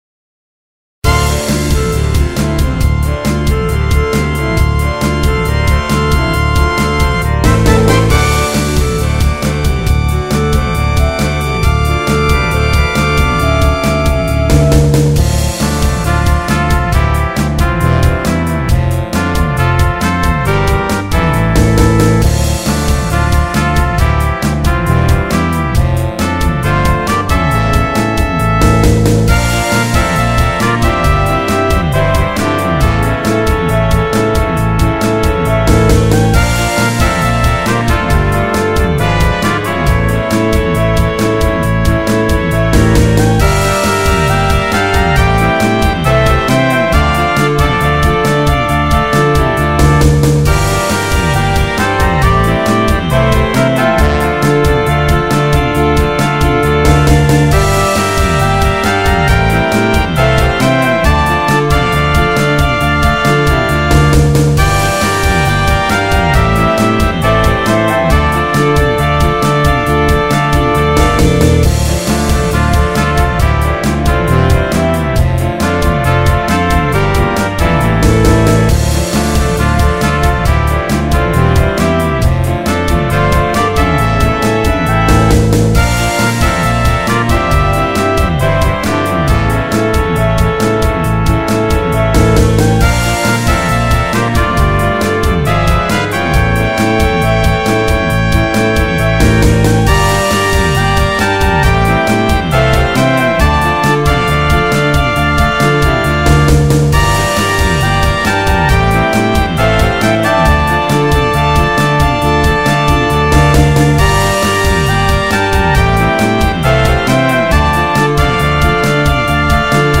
BGM
インストゥルメンタルエレクトロニカロング